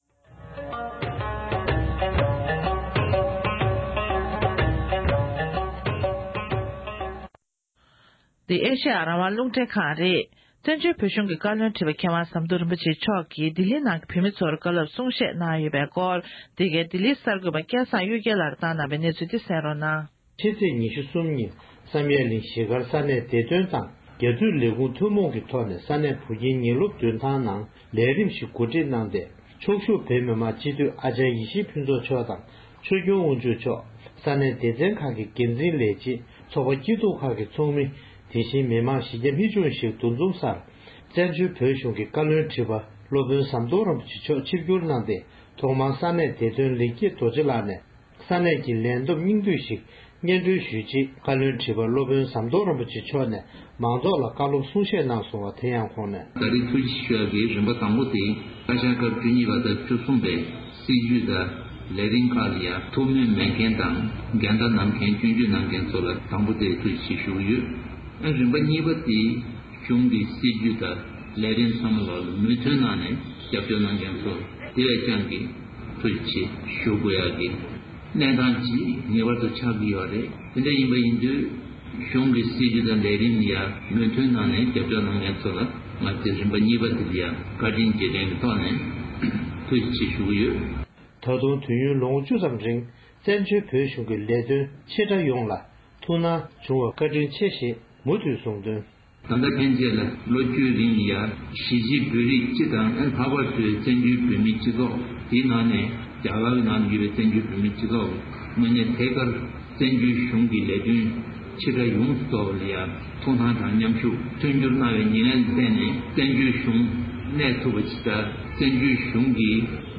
ལྡི་ལི་བོད་མི་རྣམས་ལ་བཀའ་སློབ་གསུང་བཤད། བཙན་བྱོལ་བོད་གཞུང་གི་བཀའ་བློན་ཁྲི་པ་མཁས་དབང་ཟམ་གདོང་རིན་པོ་ཆེ་མཆོག།
ལྡི་ལི་བོད་མི་རྣམས་ལ་བཀའ་སློབ་གསུང་བཤད།
སྒྲ་ལྡན་གསར་འགྱུར། སྒྲ་ཕབ་ལེན།